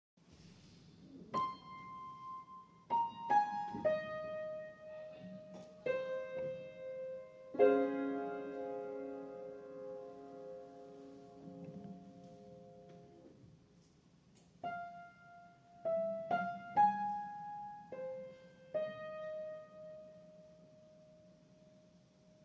Oboe Concerto
Also, please don't come at me for the notation...it's giving 6/4, but I'm just in the beginning stages of brain storming